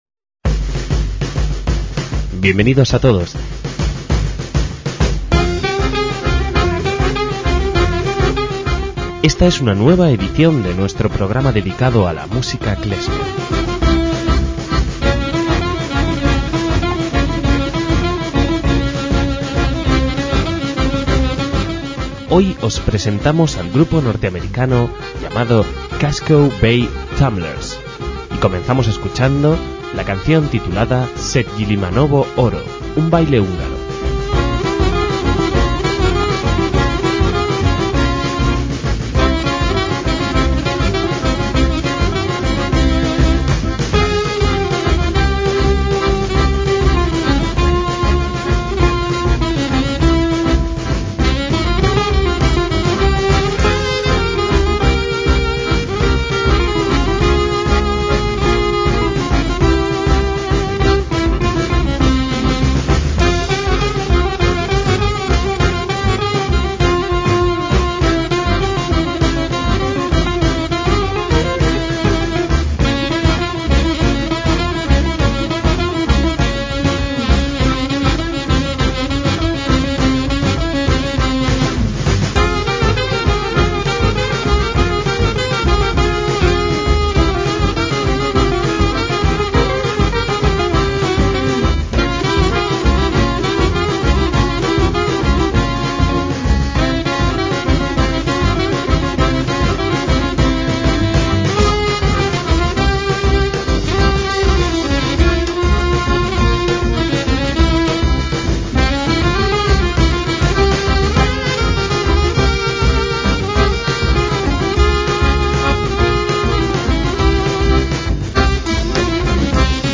MÚSICA KLEZMER
clarinetista
flautista
contrabajo
acordeón
batería